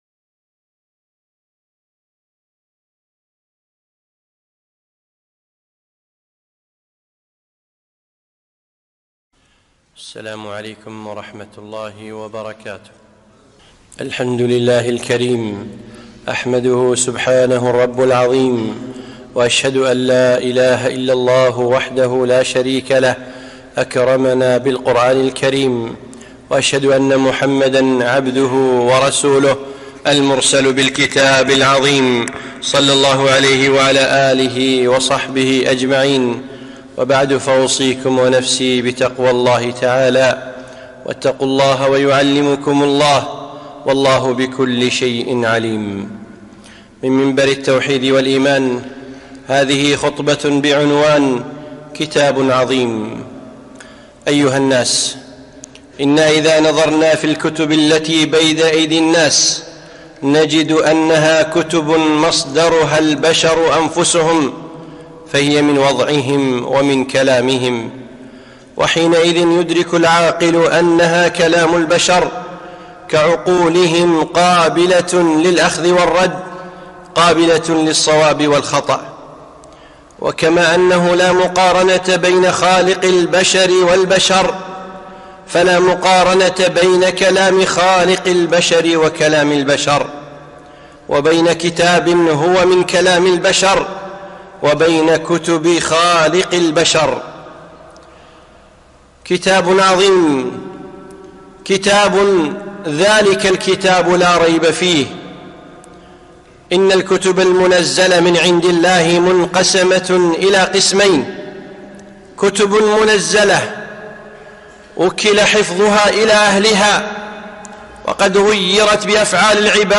خطبة - كتاب عظيم